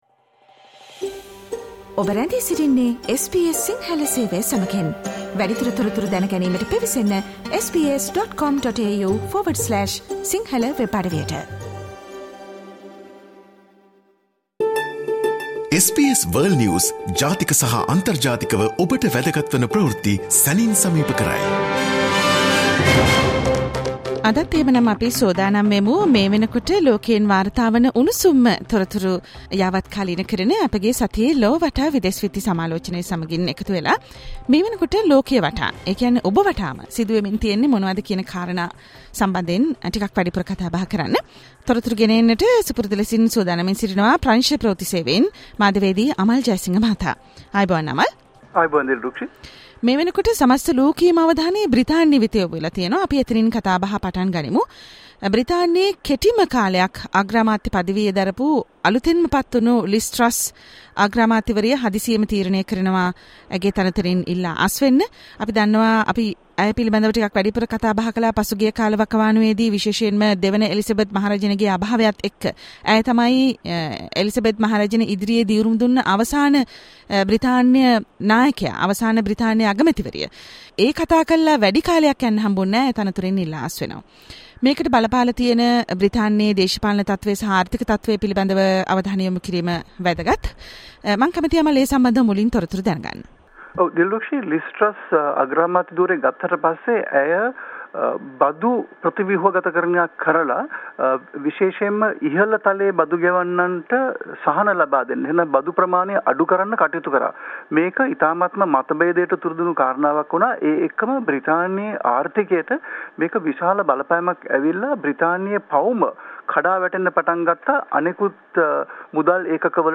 World's prominent news highlights in a few minutes - listen to the SBS Sinhala Radio weekly world News wrap every Friday ් Share